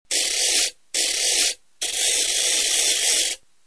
ratchet.mp3